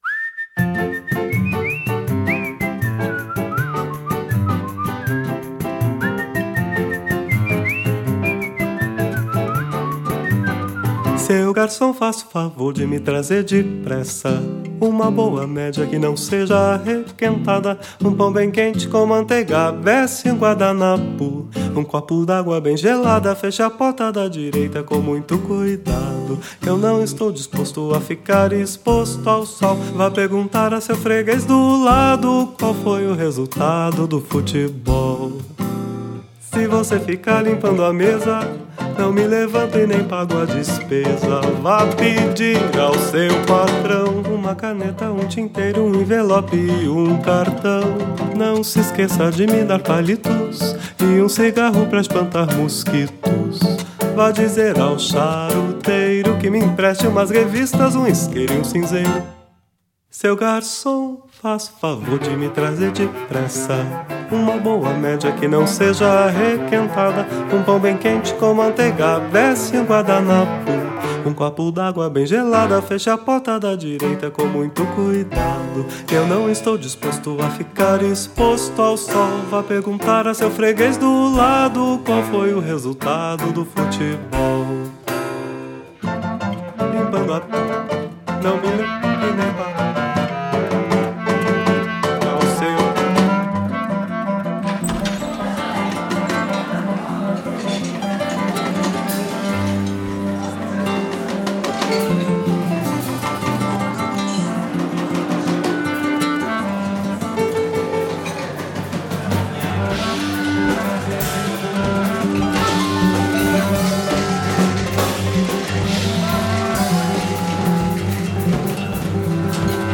A mesma variedade se encontra na escolha dos instrumentos nada convencionais: teremim, bateria, ciarone e até pente, lixa, rodas de bicicleta e latas de alumínio.